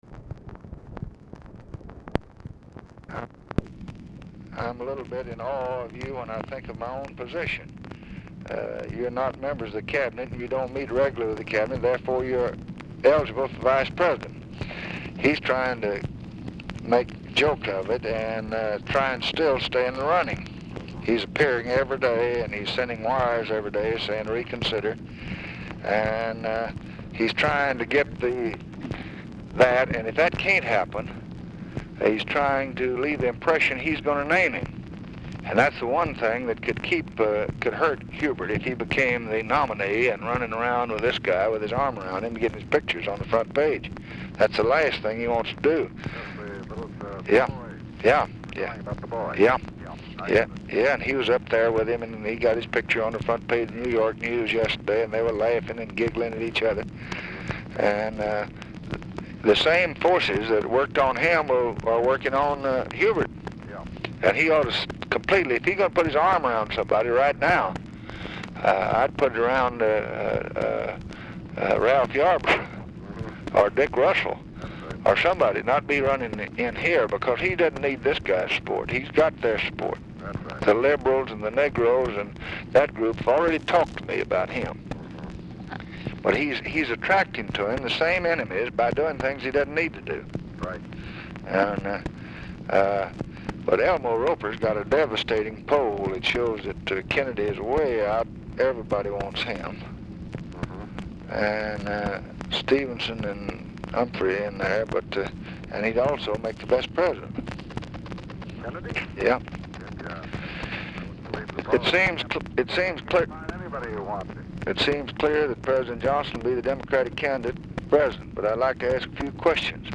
Telephone conversation # 4778, sound recording, LBJ and JAMES ROWE, 8/6/1964, 1:35PM
Format Dictation belt